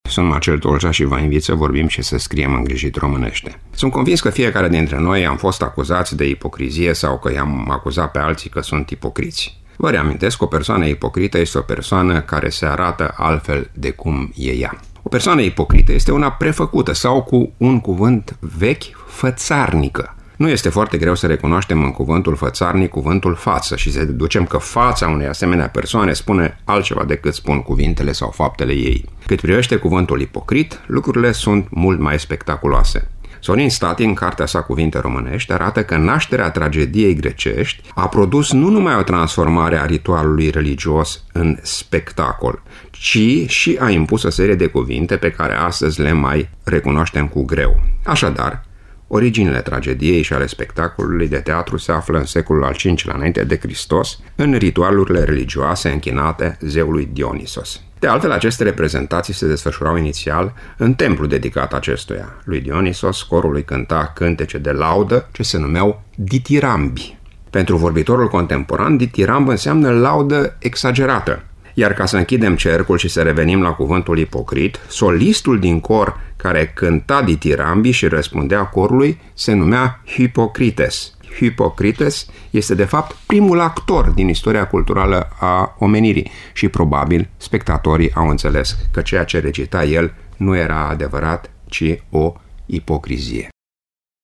(rubrică difuzată în 16 februarie 2017)